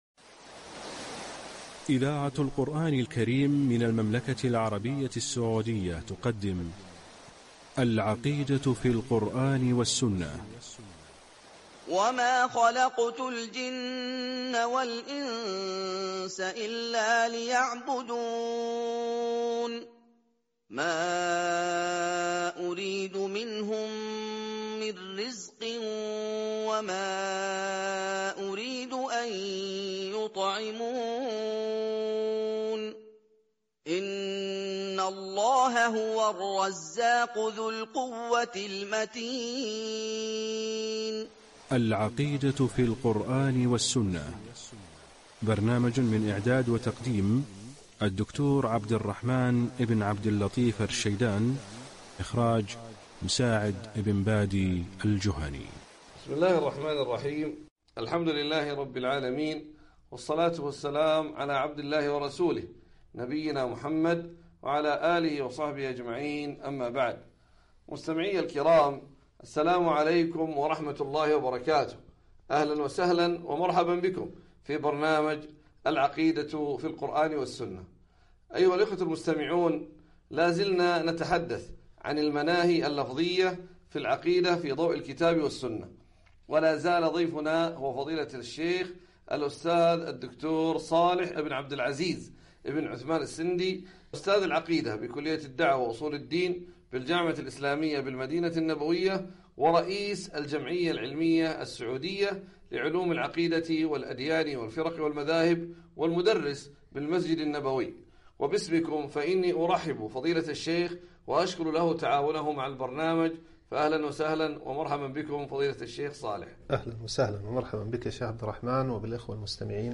برنامج إذاعي عبر إذاعة القرآن الكريم المملكة العربية السعودية